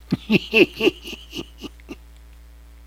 Laugh Evil Old Man